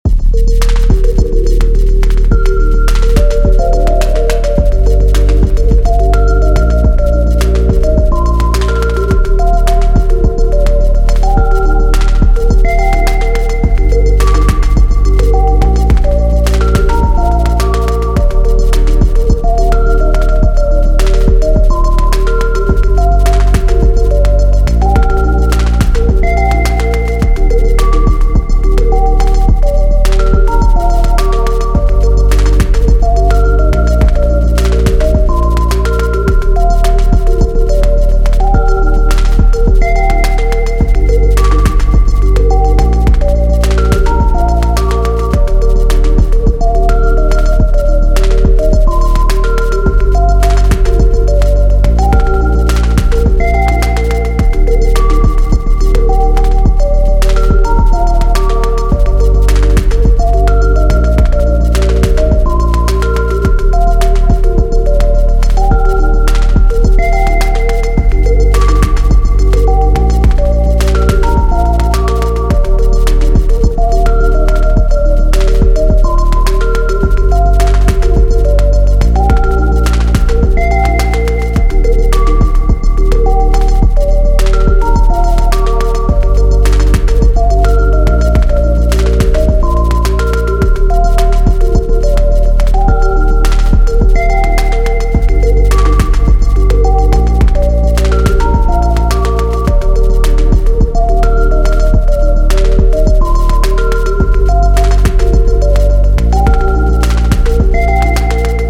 I think the random bell arp is from my routine sound pack and beats from the upcoming one.